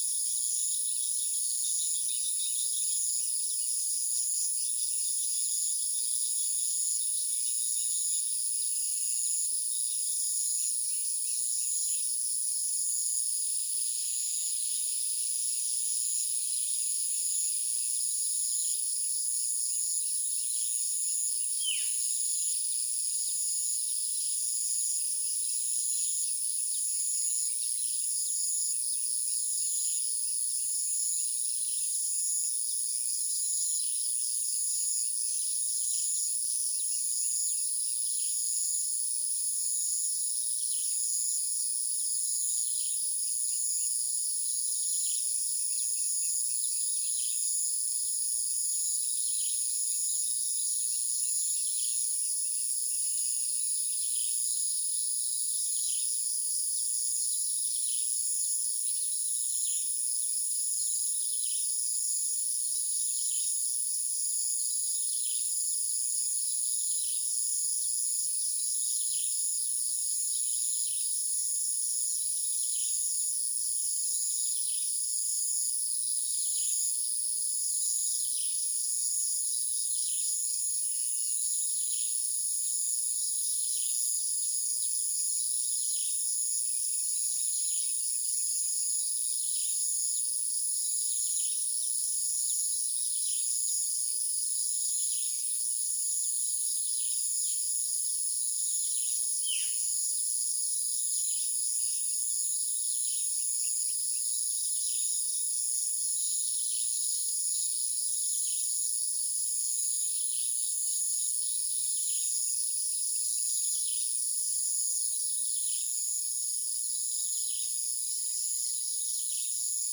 Demonstration soundscapes
60920 | anthropophony
60916 | biophony